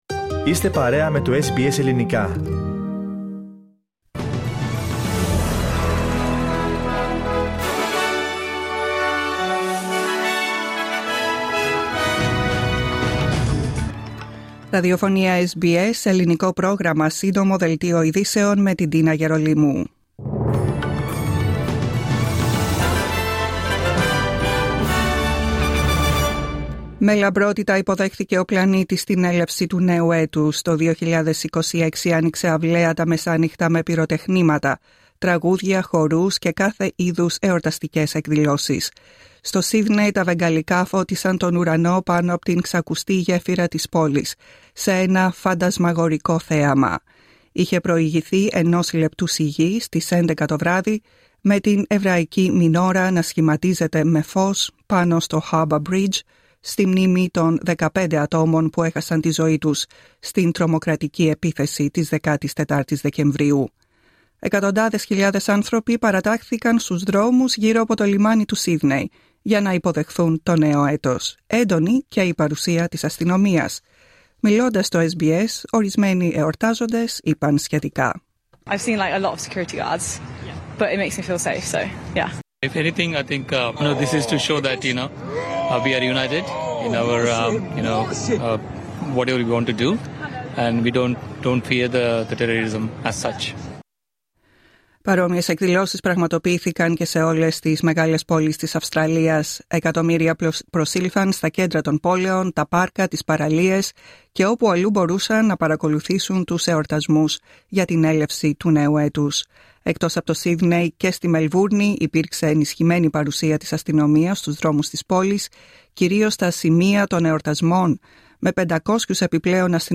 Σύντομο δελτίο ειδήσεων απ΄το Ελληνικό Πρόγραμμα της SBS.